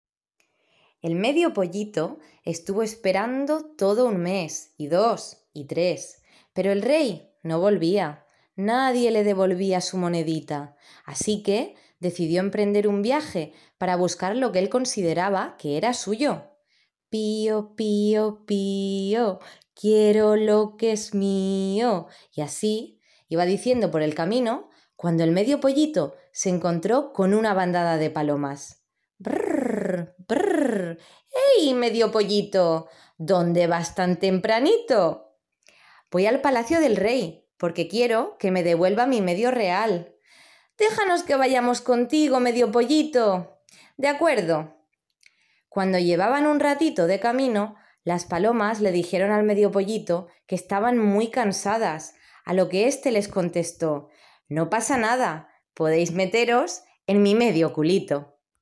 Fragmento de cuento narrado